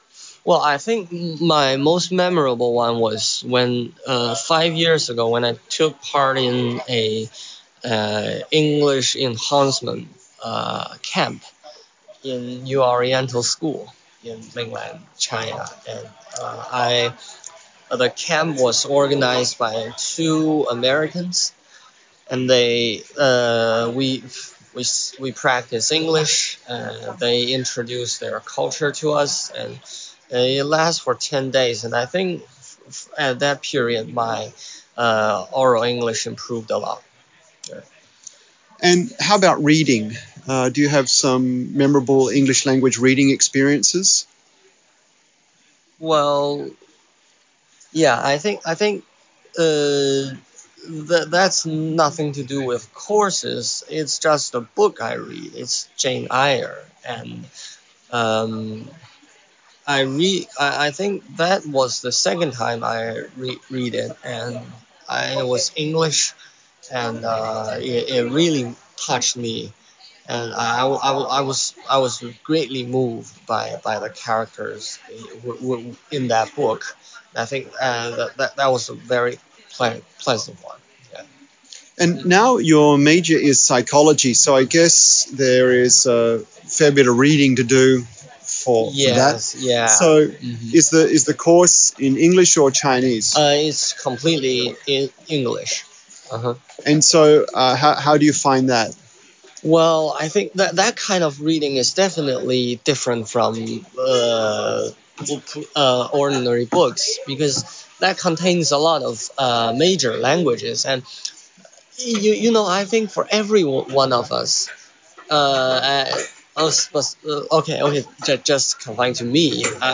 A postgraduate psychology student enjoyed an English camp organised by American tutors on the Mainland during secondary school. Jane Eyre is a really touching book.